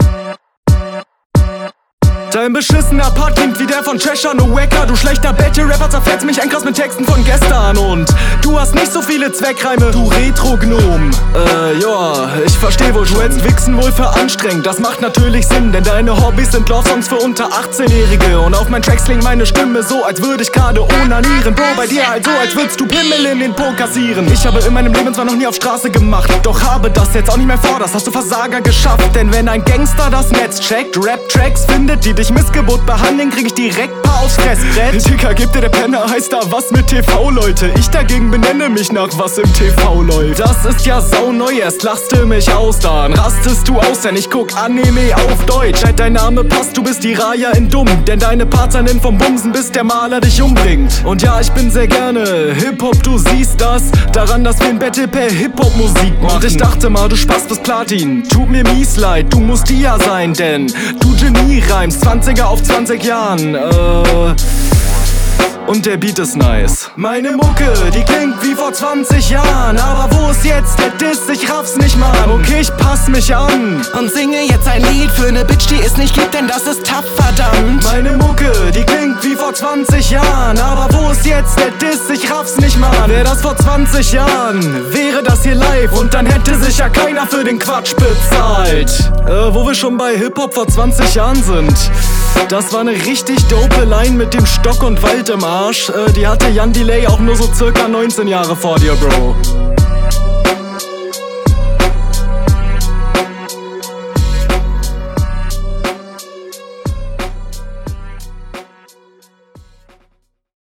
Du kommst auch sehr nice auf dem Beat.
Flow kommt ins Wanken, sobald du shuffelst oder die Doubletime-Passagen einsetzt.
- schwieriger beat für das stimmbild, harmoniert für mich leider nicht